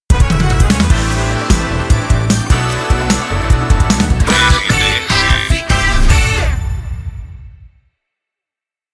Volta de Bloco Curta